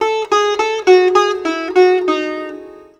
SITAR LINE27.wav